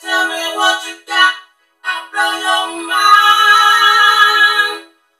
TEL ME VOC-L.wav